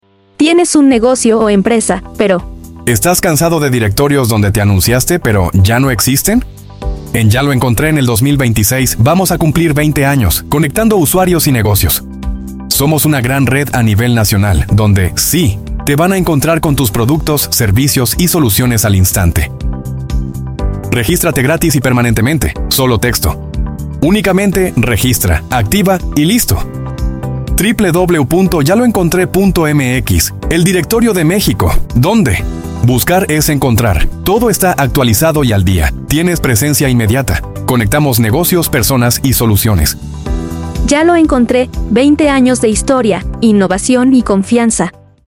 Pop en español